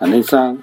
Cdo-fzho_33_(săng-sĕk-săng).ogg